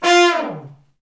Index of /90_sSampleCDs/Roland LCDP06 Brass Sections/BRS_Section FX/BRS_Fat Falls